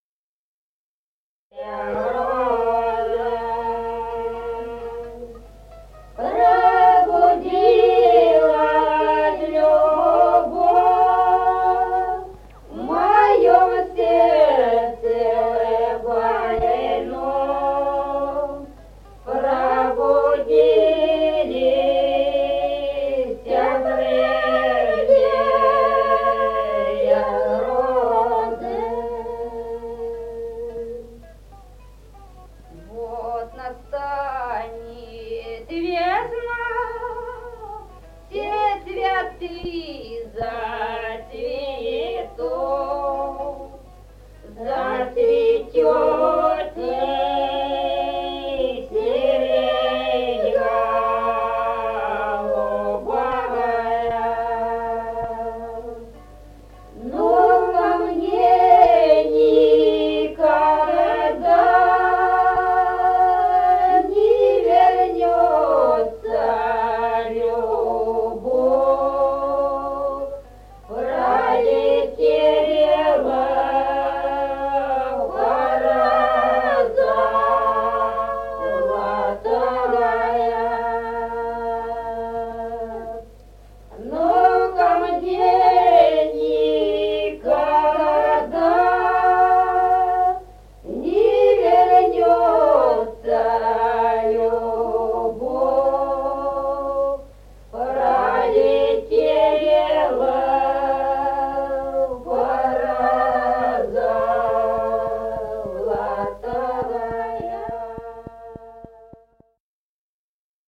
Песни села Остроглядово в записях 1950-х годов